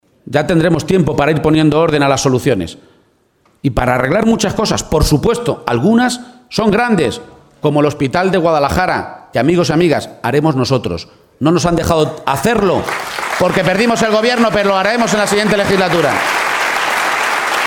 Asume cuatro compromisos firmes en su primer acto de campaña electoral, celebrado en Guadalajara